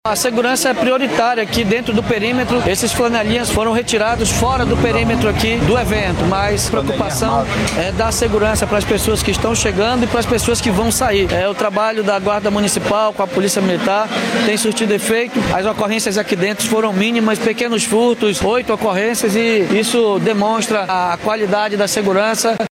Durante o festival, o prefeito de Manaus, Davi Almeida, disse que a segurança foi feita em um trabalho conjunto entre a Guarda Municipal e a Polícia Militar, que resultou na prisão de dois homens.